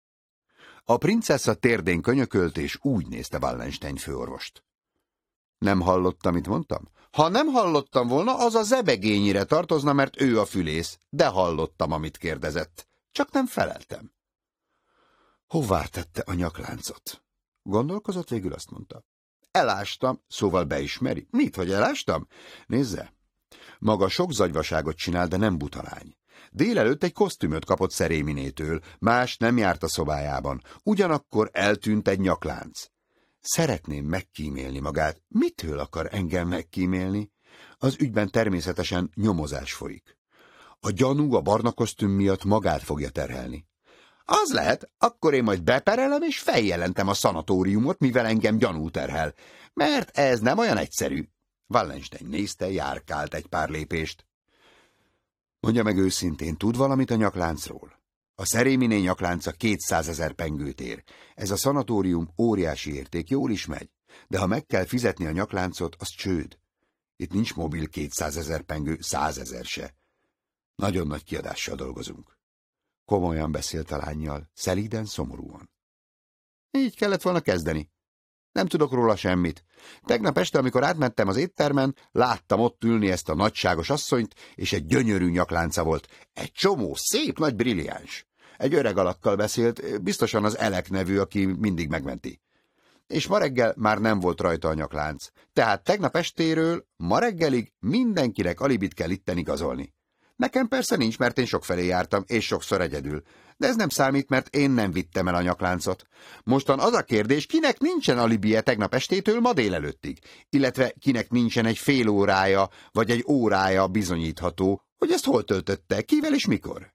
Online hangoskönyv